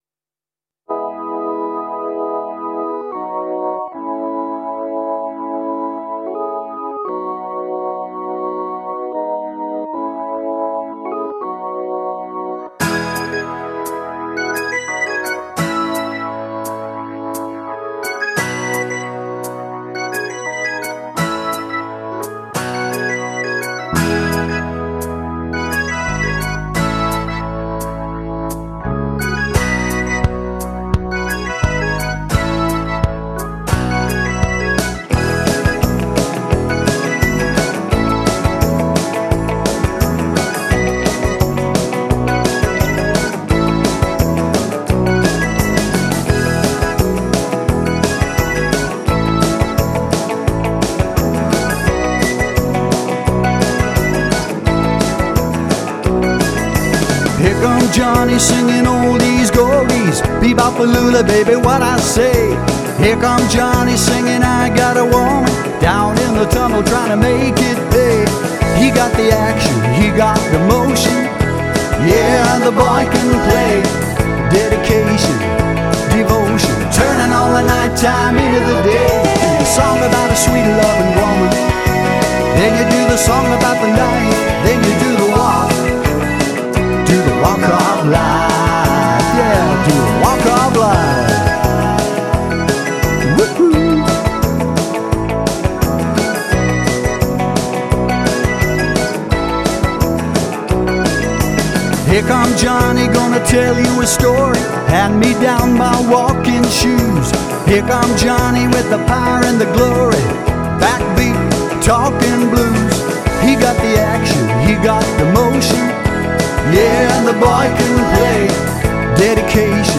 Function band